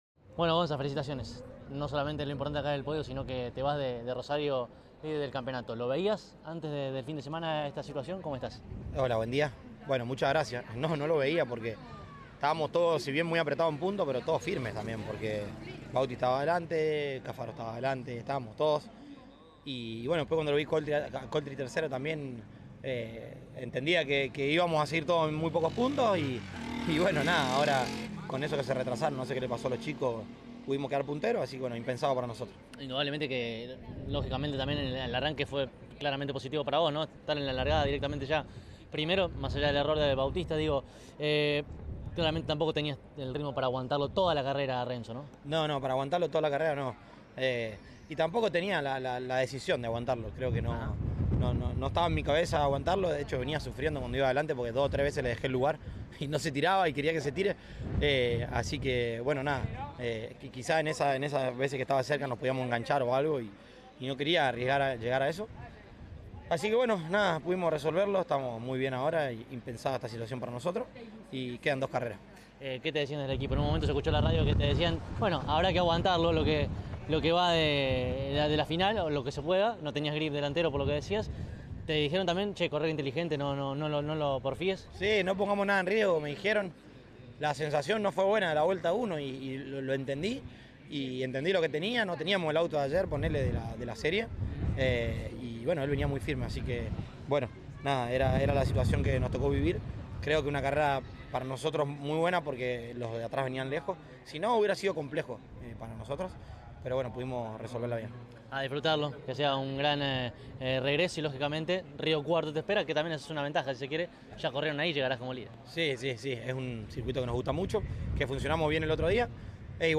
Este fin de semana, el Turismo Nacional disputó su décima fecha de la temporada y la primera de las dos finales en disputarse del domingo fue la de la Clase 2. Tras ella, los integrantes del podio, dialogaron con CÓRDOBA COMPETICIÓN.